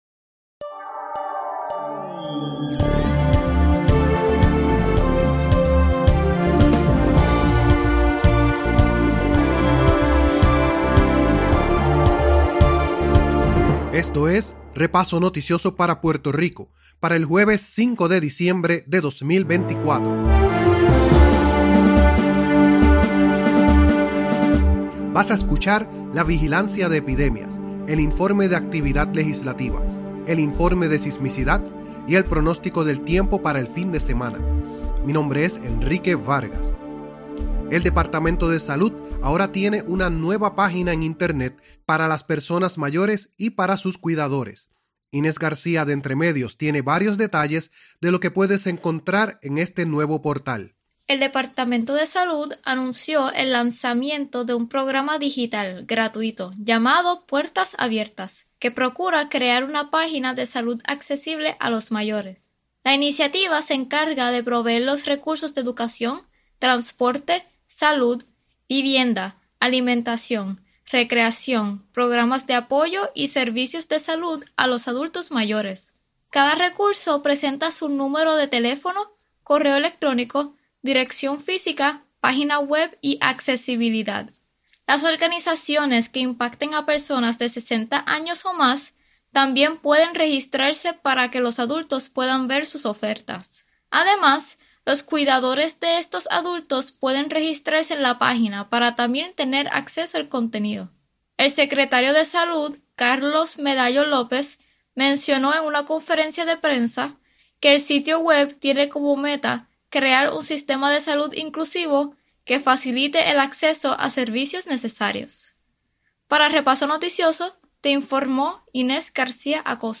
El Informe de Sismicidad es preparados y presentado por estudiantes del Recinto Universitario de Mayagüez con el apoyo de la Red Sísmica de Puerto Rico . El Pronóstico del Tiempo para el Fin de Semana es preparado y presentado por la Sociedad Meteorológica de Puerto Rico del Recinto Universitario de Mayagüez.